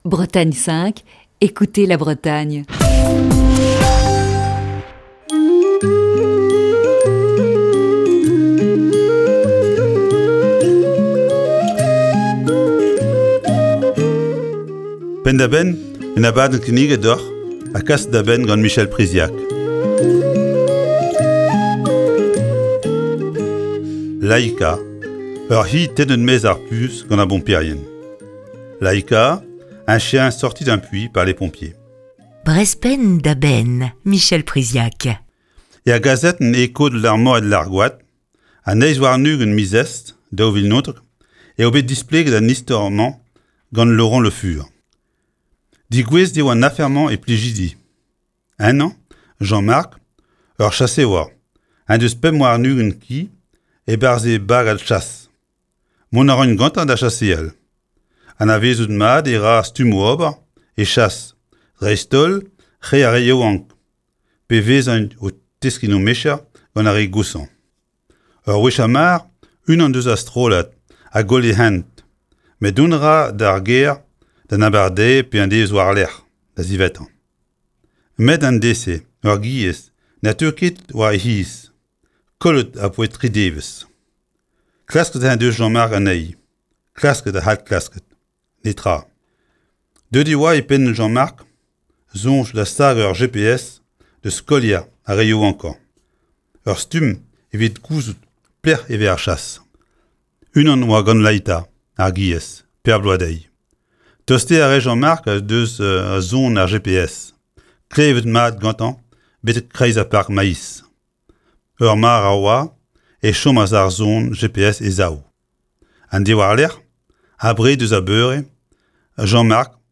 Chronique du 13 juillet 2020.